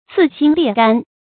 刺心裂肝 cì xīn liè gān 成语解释 谓悲痛至极。